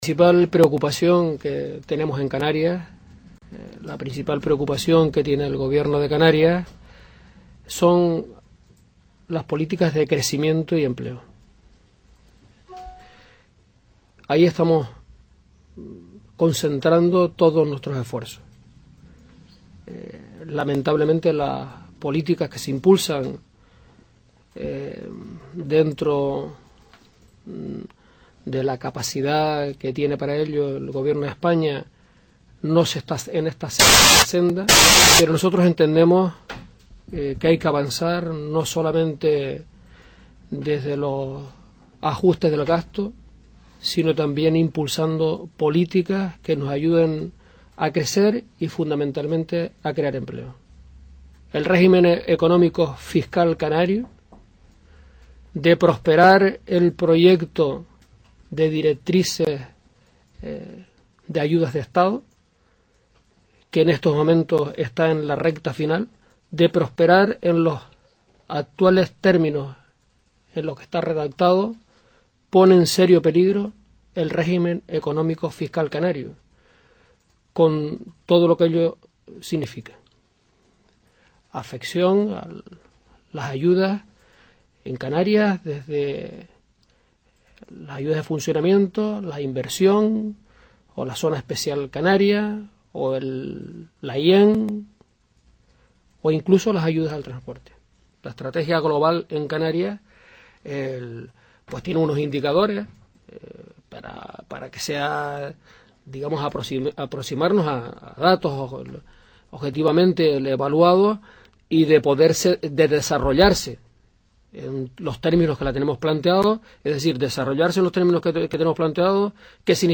El presidente del Gobierno de Canarias, Paulino Rivero, aseguró este miércoles en Bruselas que el Ejecutivo autónomo utilizará todos los mecanismos que se pongan a su alcance para combatir el desempleo y crear puestos de trabajo en el Archipiélago.
Tal y como informa el Ejecutivo canario en nota de prensa remitida a Crónicas, Rivero realizó estas declaraciones antes de reunirse con los comisarios europeos de Política Regional, Johannes Hahn; Empleo, Asuntos Sociales e Inclusión, Lázsló Andor, y de Competencia, Joaquín Almunia, a quienes trasladará esta tarde las líneas maestras de la Estrategia para el Crecimiento y el Empleo en Canarias